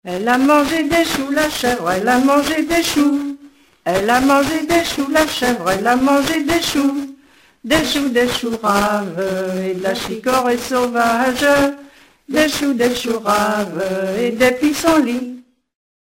Divertissements d'adultes - Couplets à danser
Pièce musicale éditée